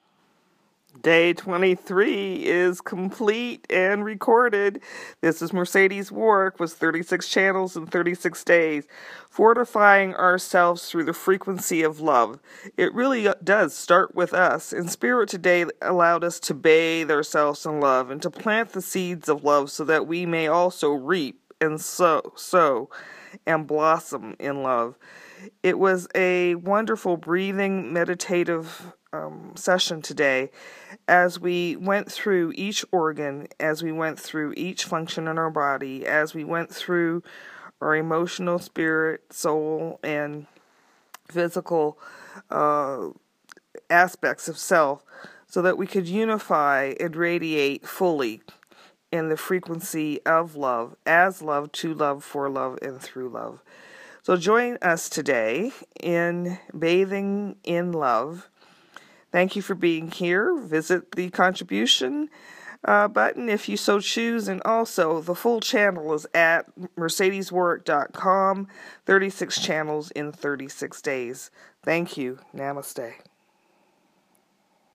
Each Channel is RECORDED in the morning and then POSTED on the 36 CHANNELS IN 36 DAYS web page later in the day.
HERE IS TODAY’S INTRODUCTION!